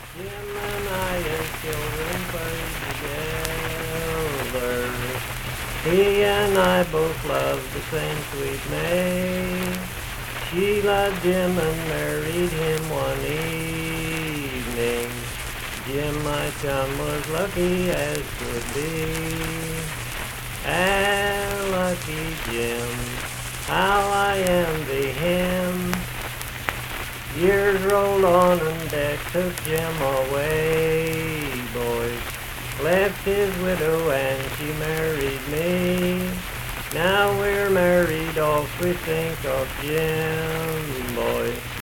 Lucky Jim - West Virginia Folk Music | WVU Libraries
Unaccompanied vocal music performance
Verse-refrain 2(3-5).
Voice (sung)